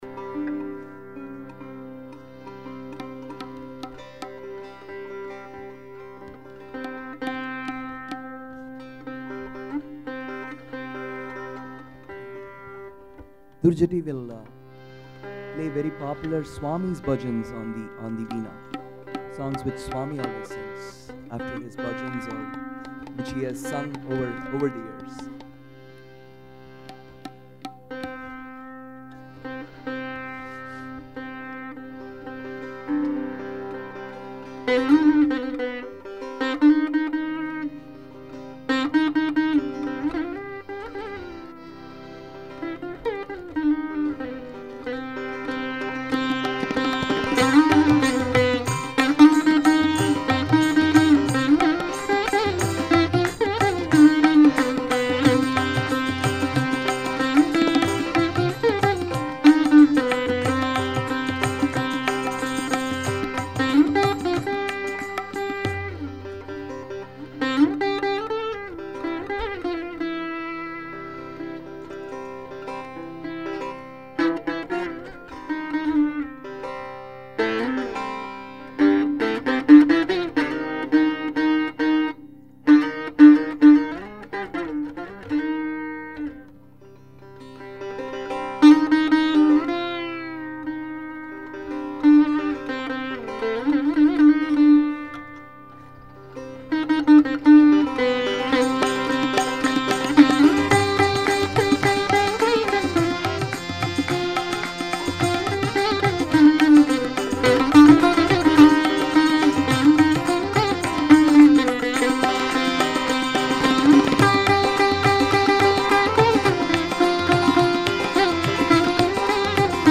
32_Swami_bhajans_instrumental.mp3